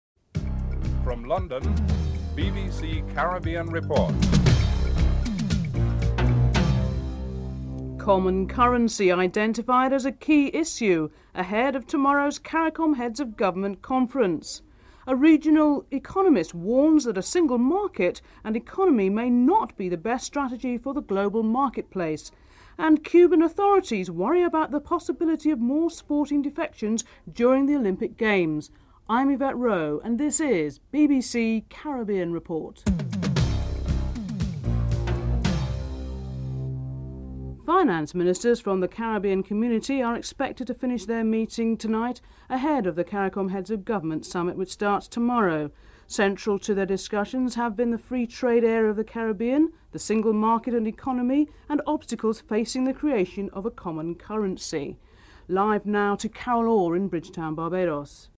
1. Headlines (00:00:36)
3. According to Finance Minister Brian Kuei Tung Trinidad and Tobago would have a hard time taking sides in any Cuba United States dispute. Finance Minister Brian Kuei Tung is interviewed (04:26-05:35)